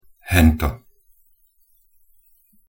pronunciation_sk_hento.mp3